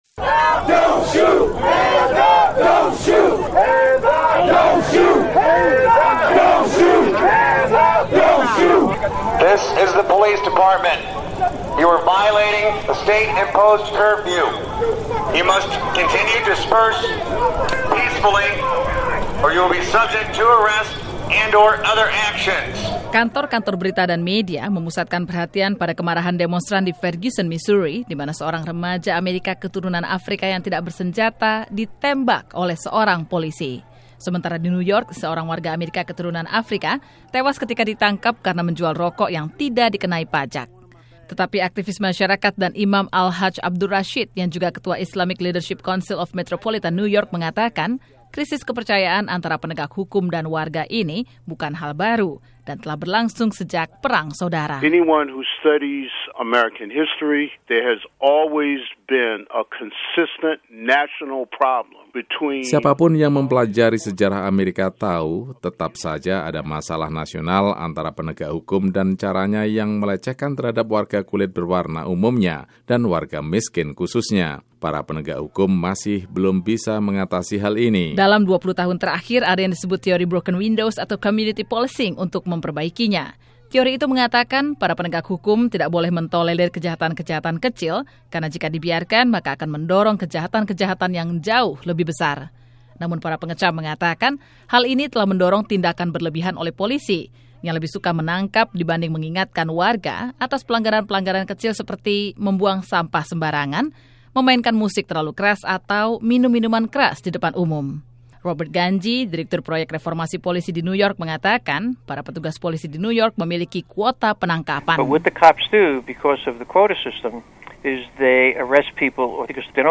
menyampaikan laporan tentang kontroversi strategi dan praktek yang dilakukan polisi terkait dua kasus kematian warga sipil tidak bersenjata di New York dan Ferguson-Missouri baru-baru ini. Apa yang harus diperbaiki untuk mencegah terulangnya insiden serupa di kemudian hari?